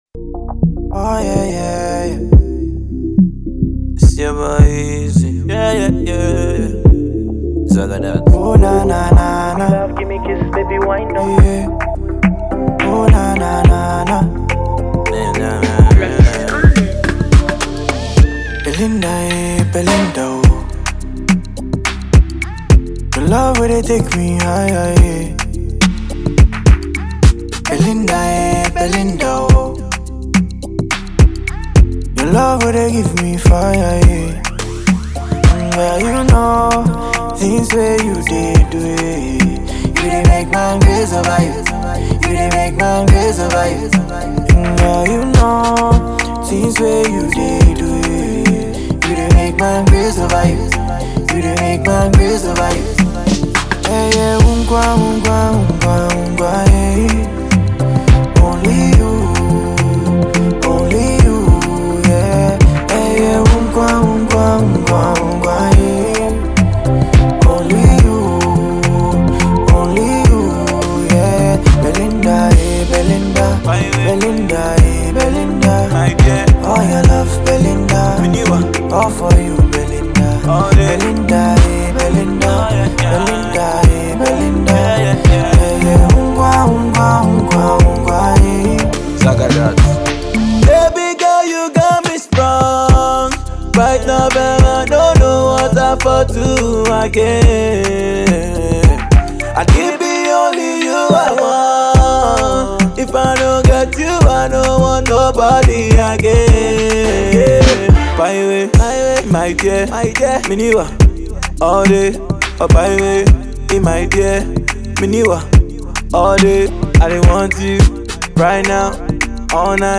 afrobeat singles